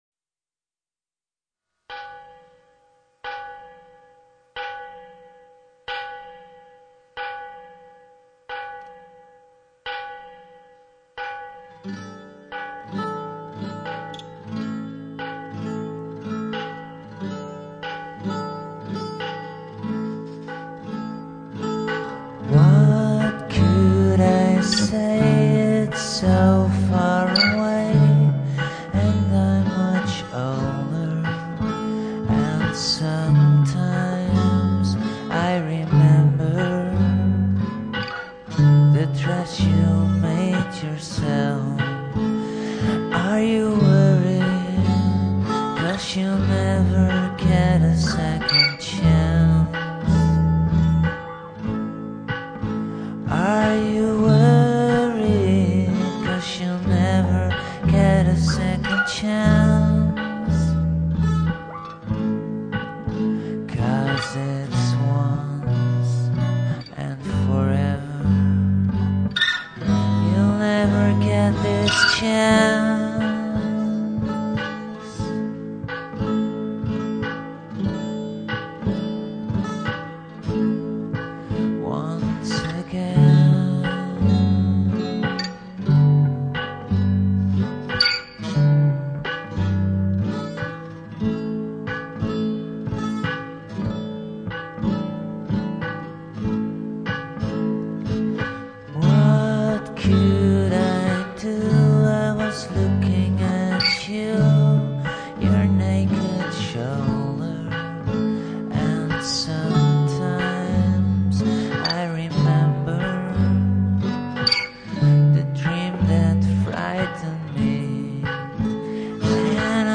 living room
A spoon wrapped in a (clean) sock used as drumstick